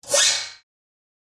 OGDEKO_percussion_21go.wav